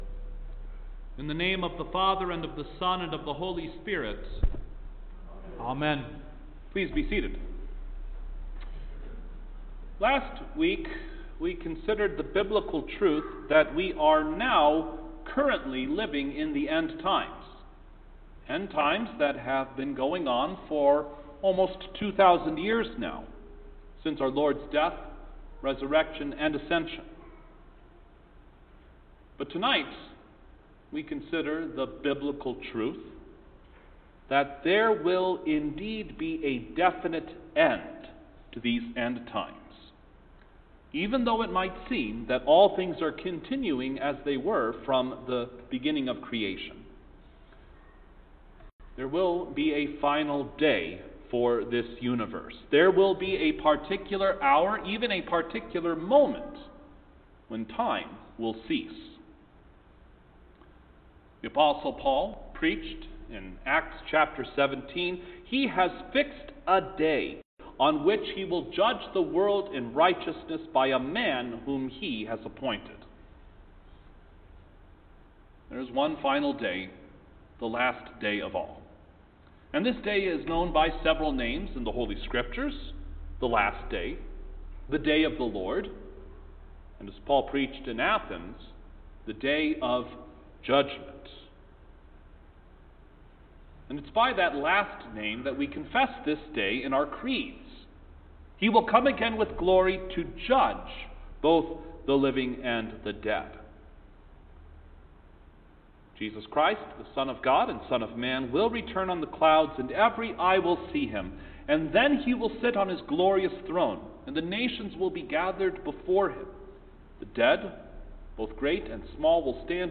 December-9_Second-Midweek-Service-in-Advent_Sermon.mp3